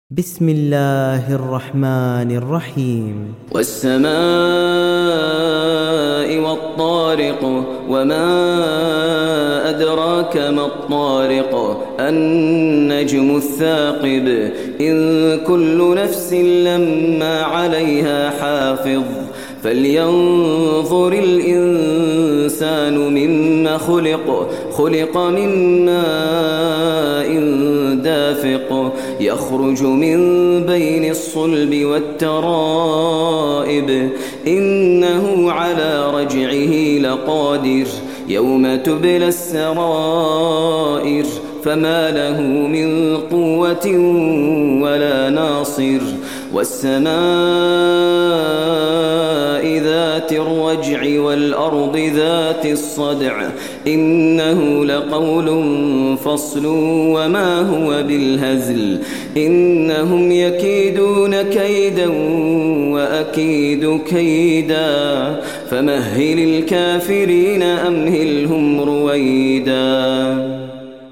دانلود سوره الطارق mp3 ماهر المعيقلي روایت حفص از عاصم, قرآن را دانلود کنید و گوش کن mp3 ، لینک مستقیم کامل